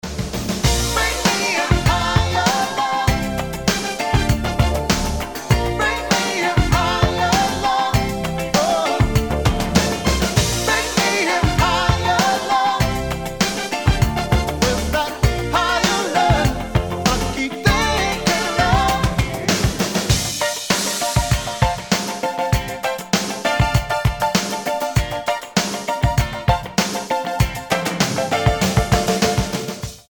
Pop Rock
вдохновляющие
ретро
Blues